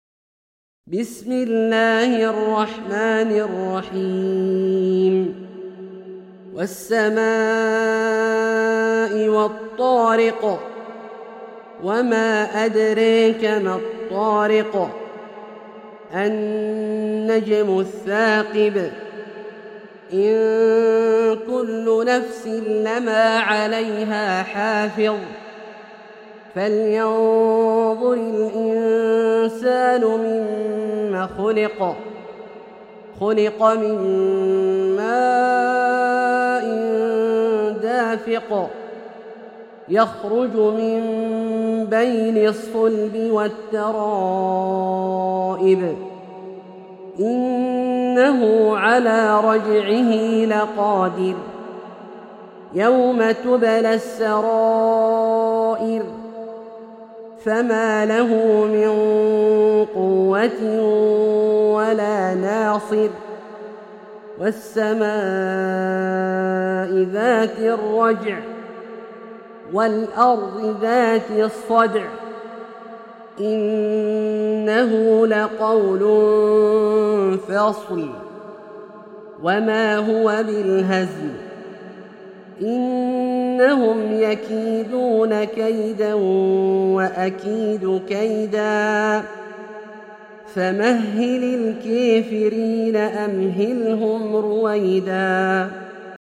سورة الطارق - برواية الدوري عن أبي عمرو البصري > مصحف برواية الدوري عن أبي عمرو البصري > المصحف - تلاوات عبدالله الجهني